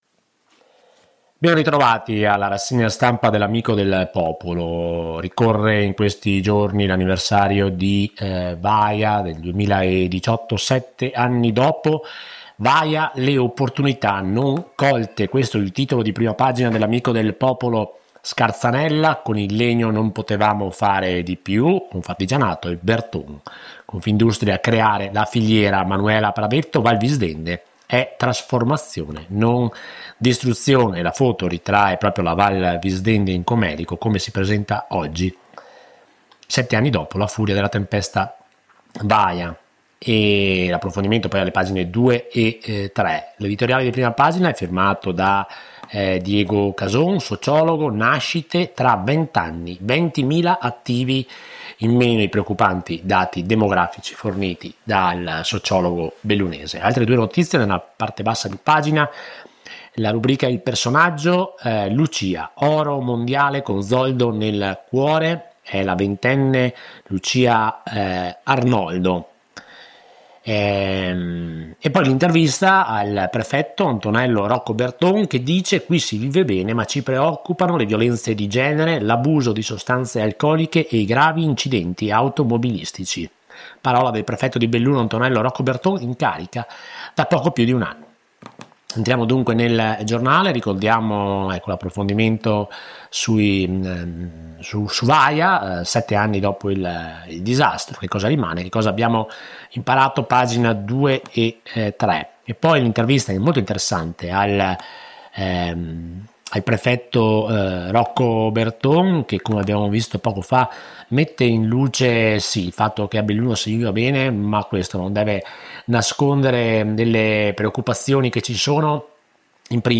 LA RASSEGNA STAMPA DA L’AMICO DEL POPOLO – 01 NOVEMBRE 2025